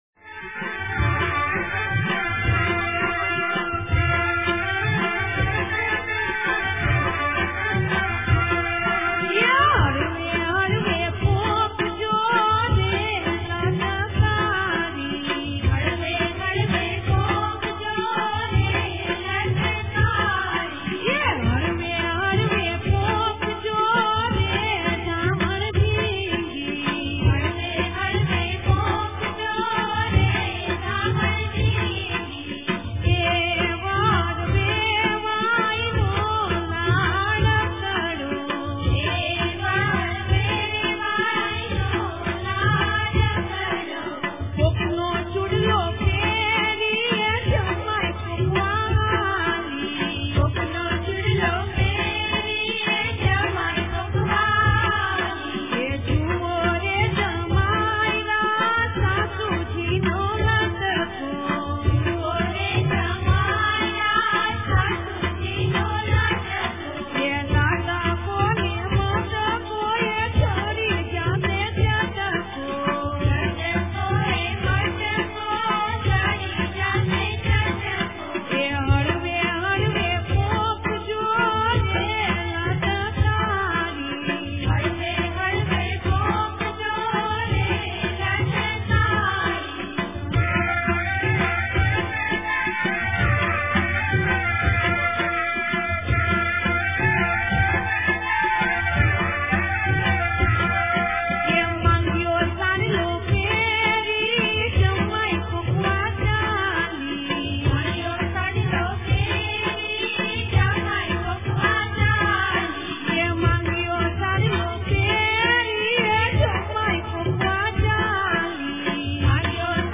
(પોંખણા વખતે વરપક્ષે ગવાતું ફટાણું)